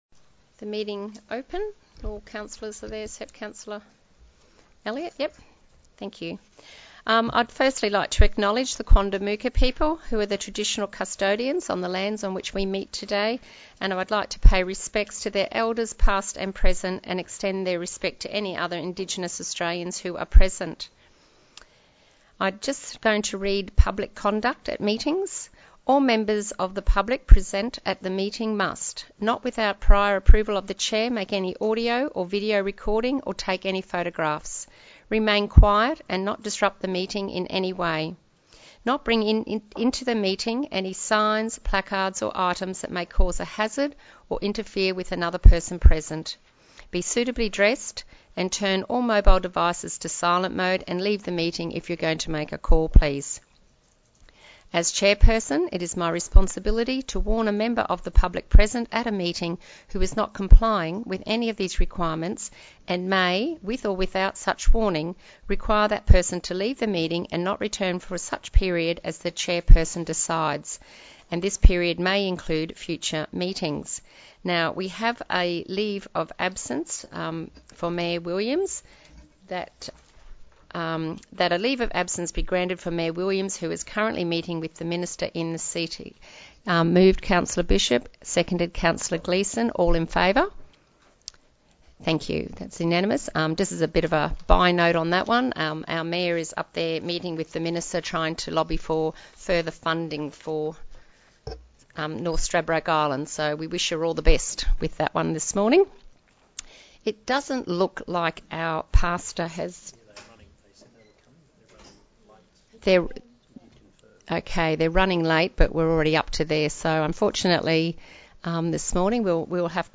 General Meeting